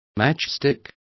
Complete with pronunciation of the translation of matchstick.